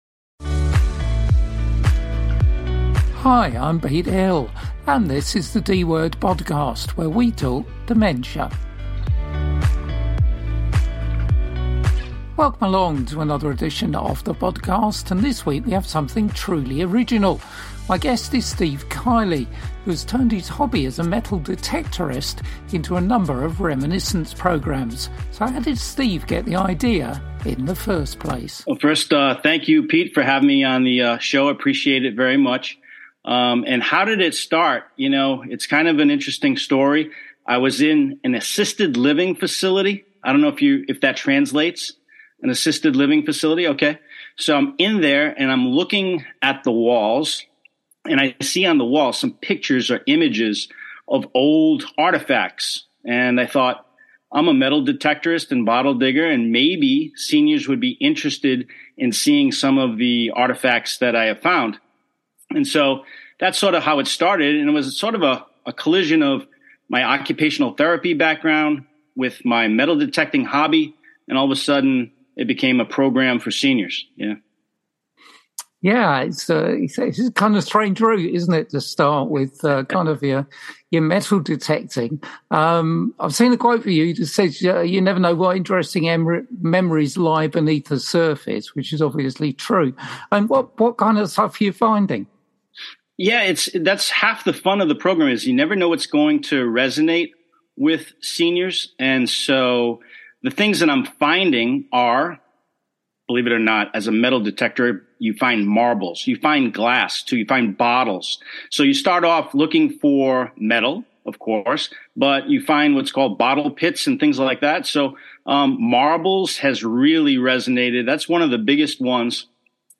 The ‘D’ Word is the UK’s only dementia-focused radio show.